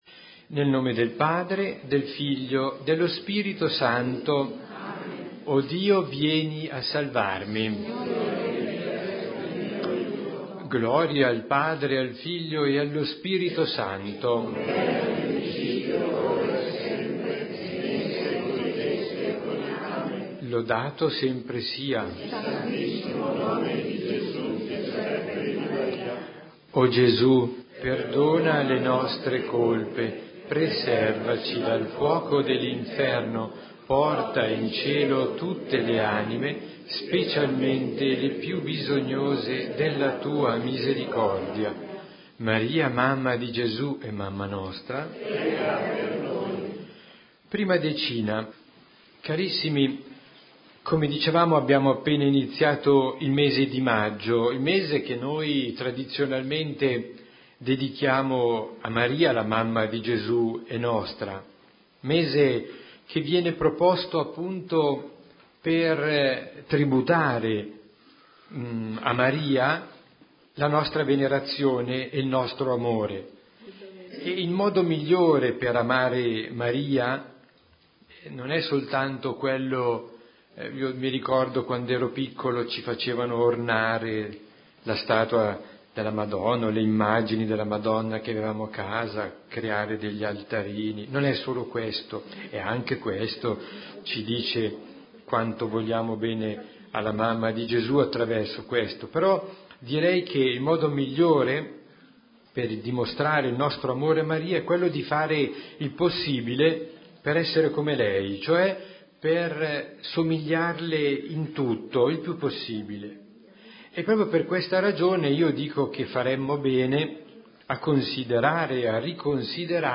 Santo Rosario